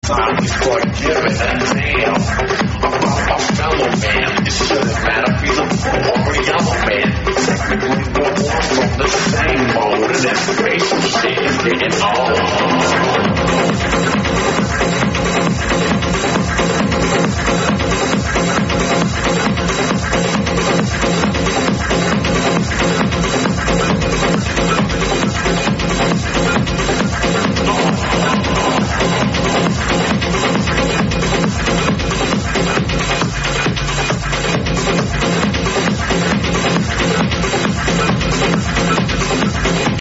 some techno track